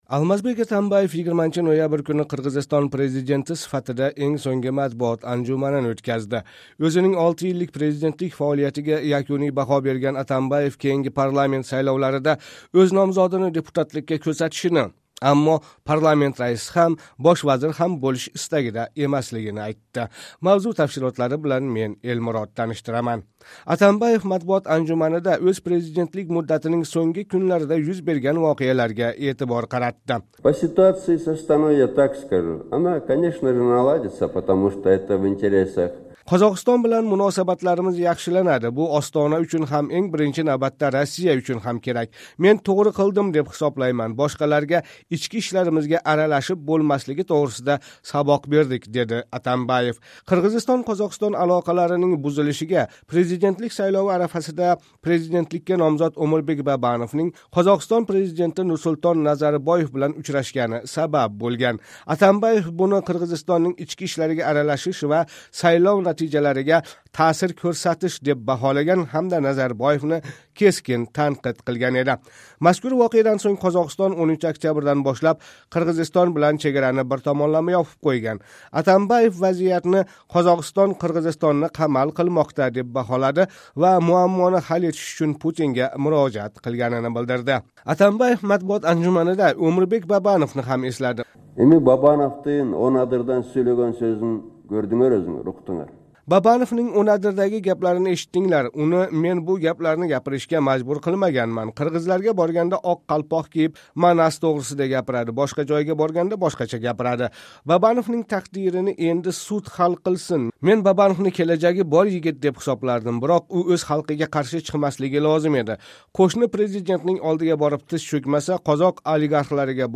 Атамбаев президент сифатидаги энг сўнгги матбуот анжуманини ўтказди
Алмазбек Атамаев 20 ноябрь куни Қирғизистон президенти сифатида энг сўнгги матбуот анжуманини ўтказди. Ўзининг 6 йиллик президентлик фаолиятига якуний баҳо берган Атамбаев кейинги парламент сайловларида ўз номзодини депутатликка кўрсатишини, аммо парламент раиси ҳам, бош вазир ҳам бўлиш истагида эмаслигини айтди.